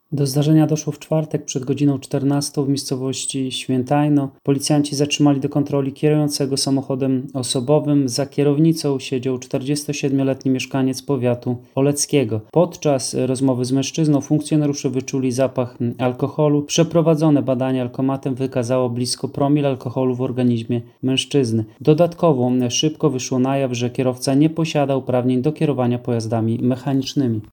Mówił Radiu 5